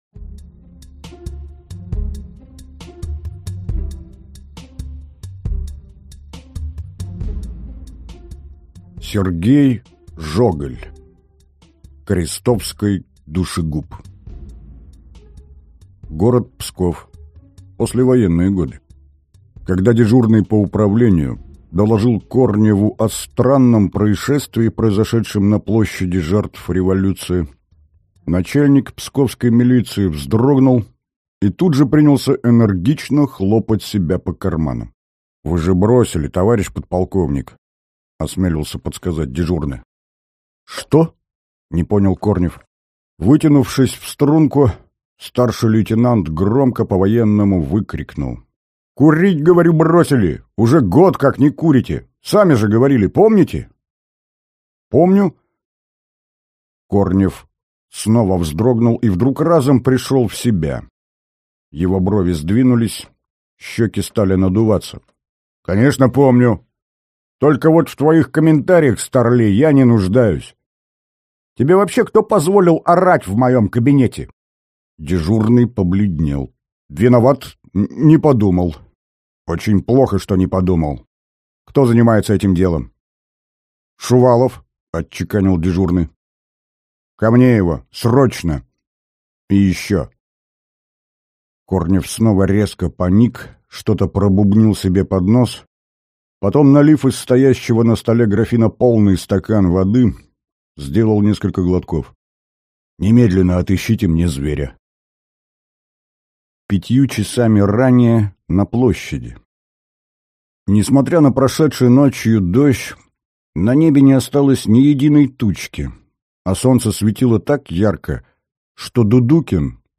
Аудиокнига Крестовский душегуб | Библиотека аудиокниг
Прослушать и бесплатно скачать фрагмент аудиокниги